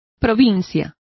Complete with pronunciation of the translation of county.